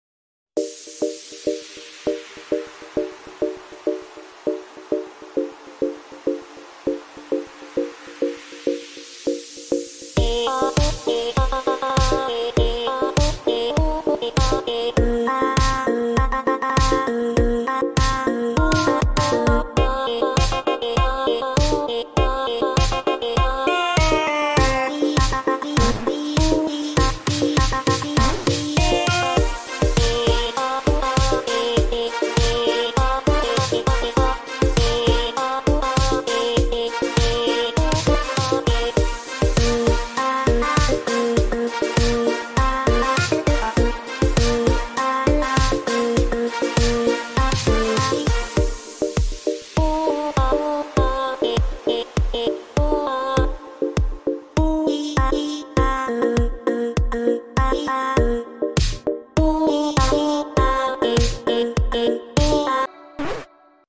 slowed edition